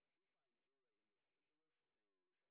sp07_train_snr10.wav